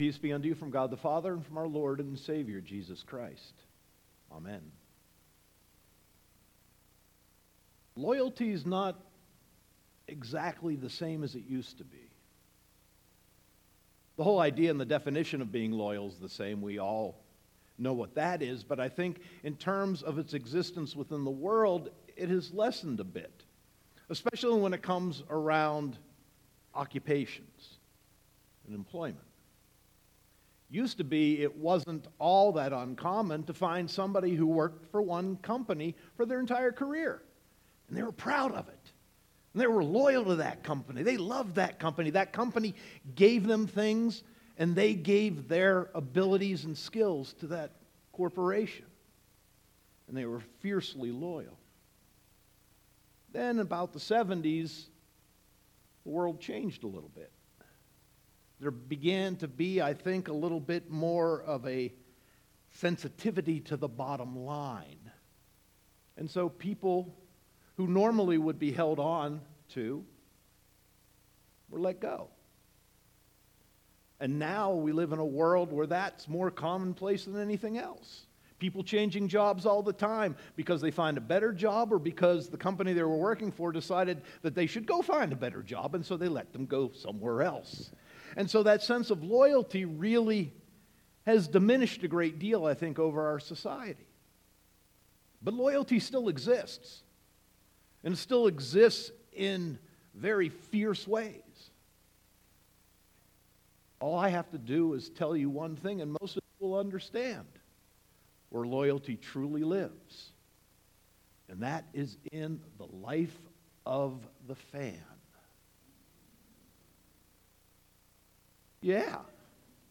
Sermon 1.28.2018